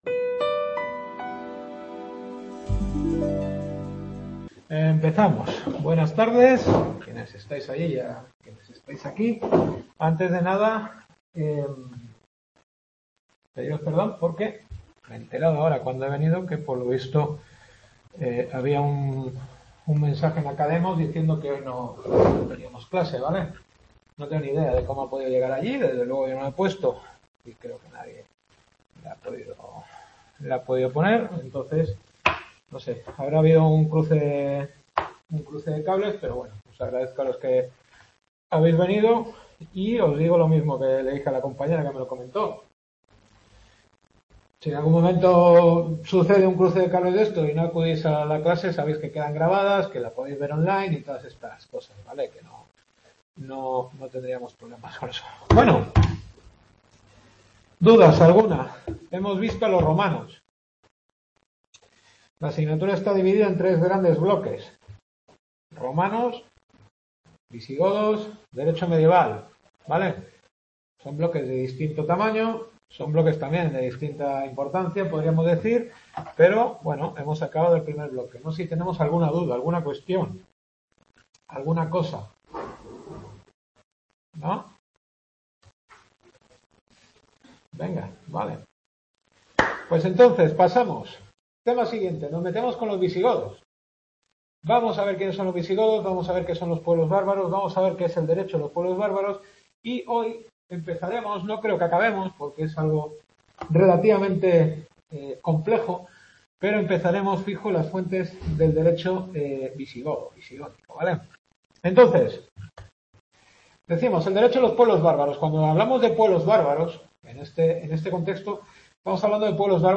Historia del Derecho. Cuarta clase.